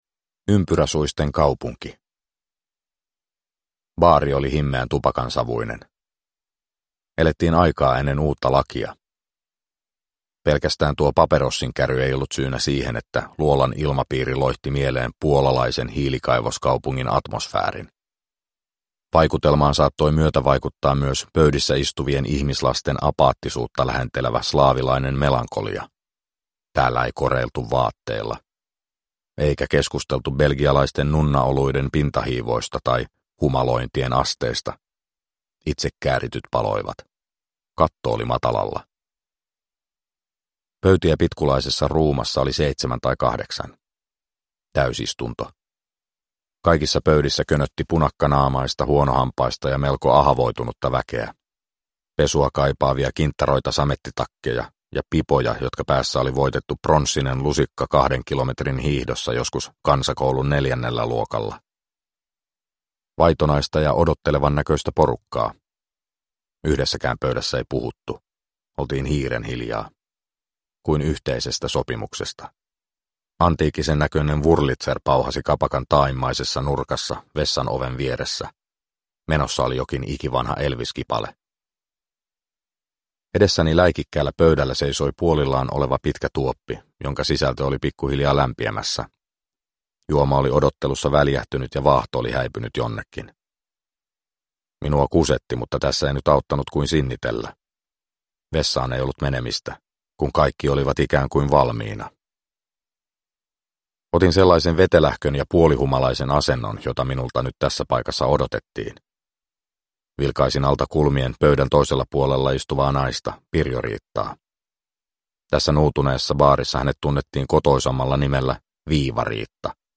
Sahanpururevolveri 3 – Ljudbok – Laddas ner